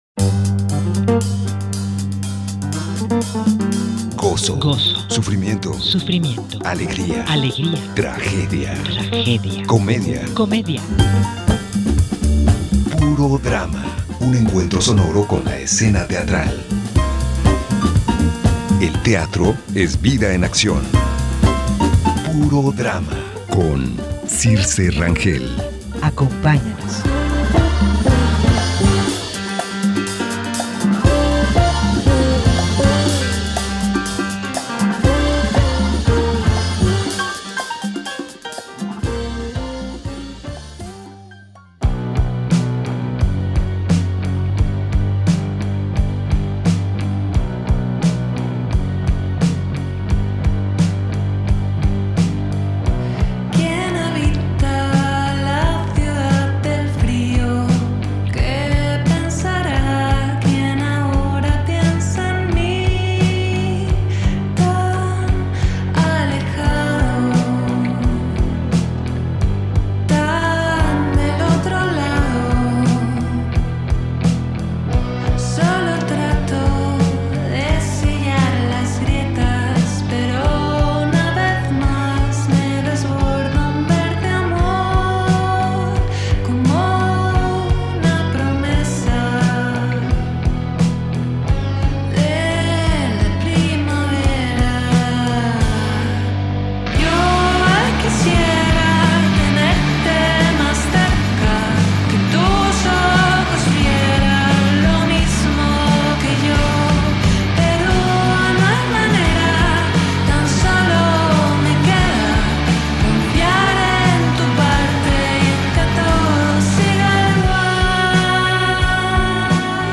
En esta entrevista nos da los pormenores de la convocatoria de nivelación para el ciclo 2026 B. El conocido actor Rodrigo Murray vuelve al teatro con el monólogo Leonardo , una pieza escénica en la que rescata el lado humano del artista Leonardo da Vinci.